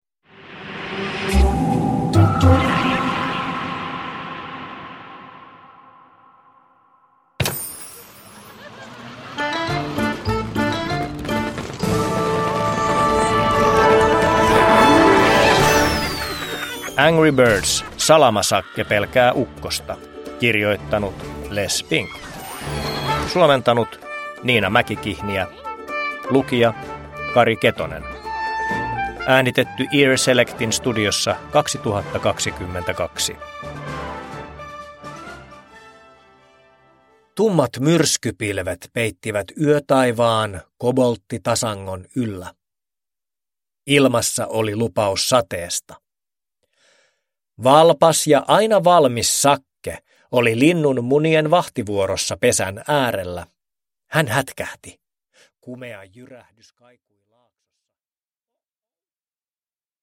Angry Birds: Salama-Sakke pelkää ukkosta – Ljudbok – Laddas ner
Uppläsare: Kari Ketonen